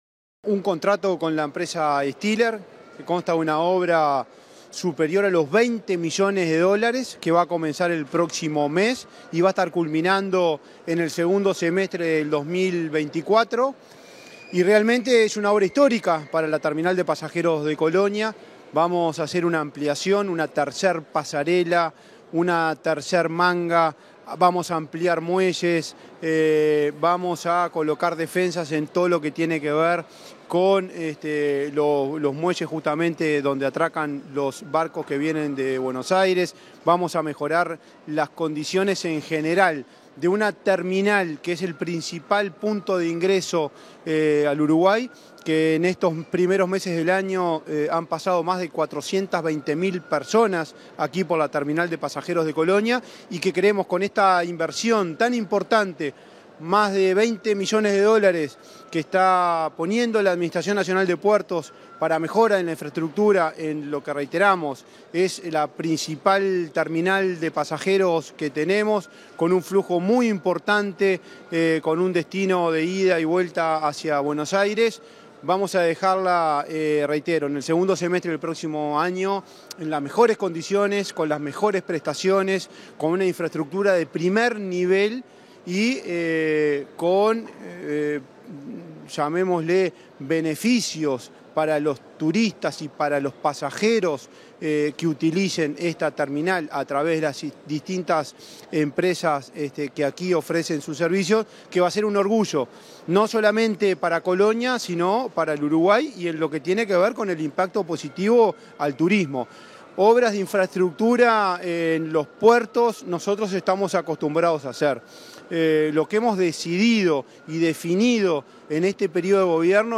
Entrevista al presidente de la Administración Nacional de Puertos, Juan Curbelo